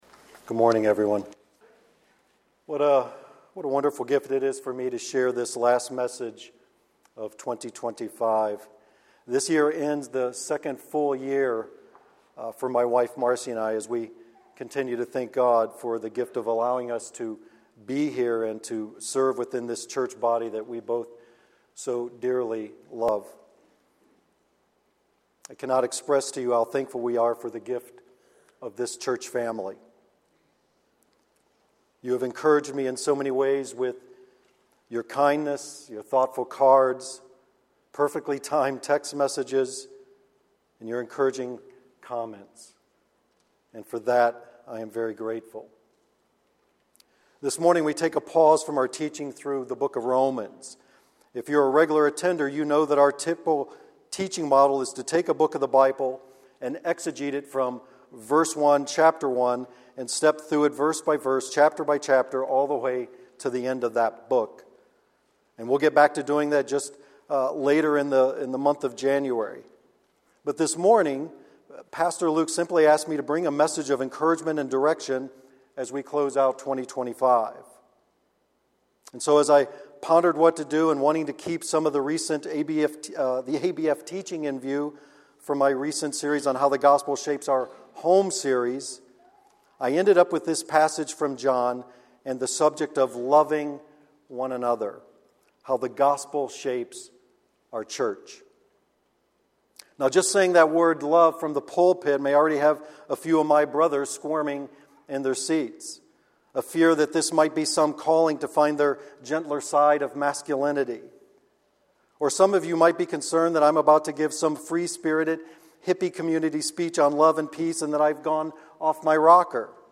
A sermon from the series "Individual Sermons."